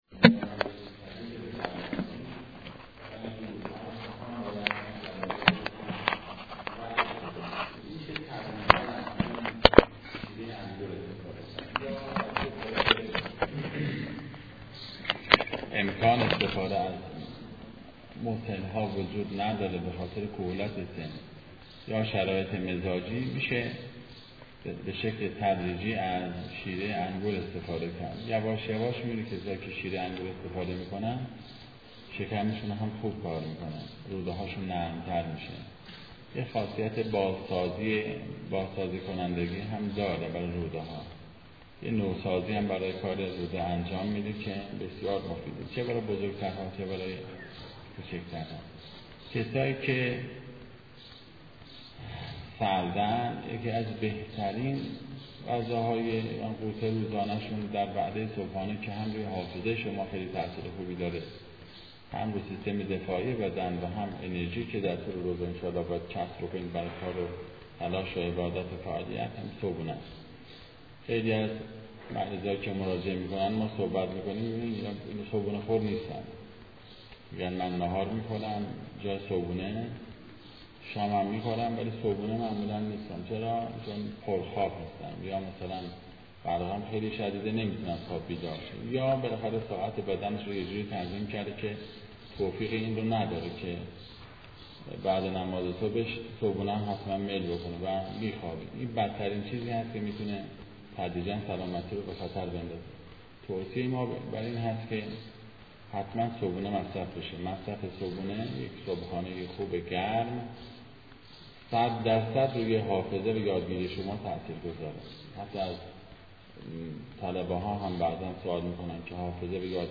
جلسه طب اسلامی
قم مسجد خاتم الانبیاء پردیسان